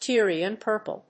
アクセント・音節Týr・i・an púrple [dýe] 発音記号・読み方/tíriən‐/